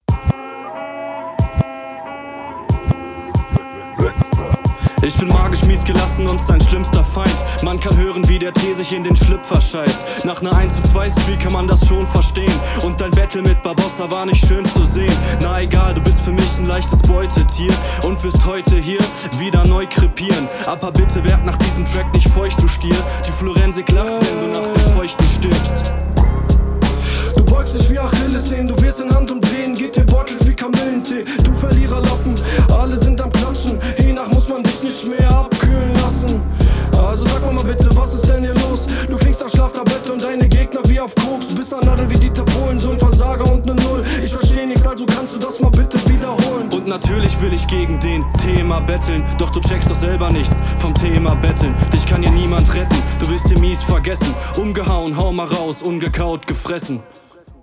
Solide geflowt, Stimme auch stabil.